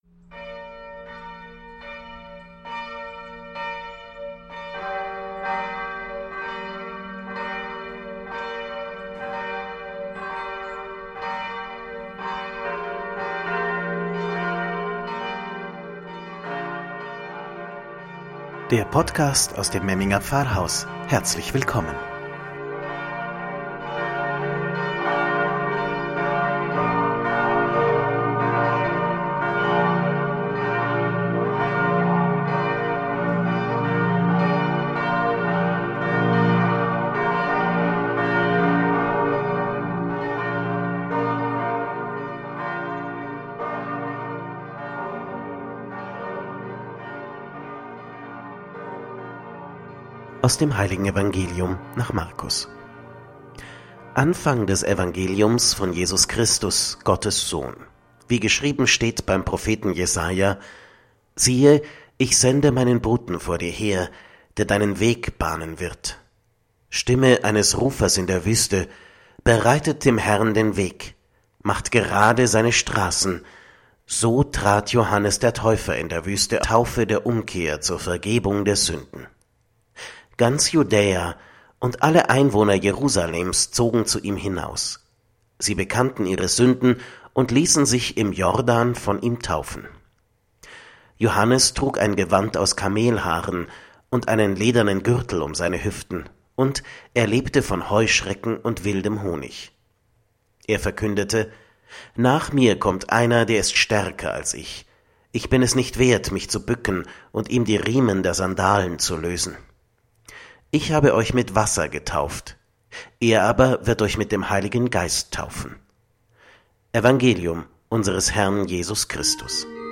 „Wort zum Sonntag“ aus dem Memminger Pfarrhaus – Zweiter Adventsonntag 2020